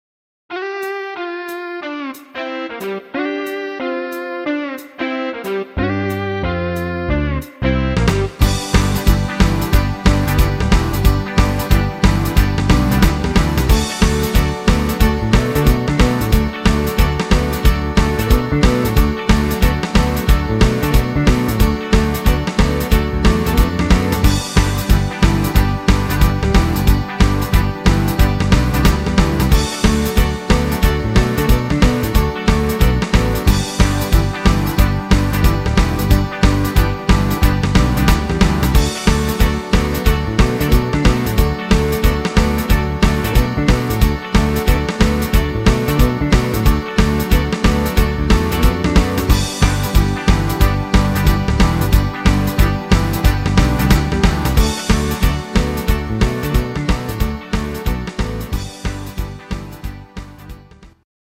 Instrumental Gitarre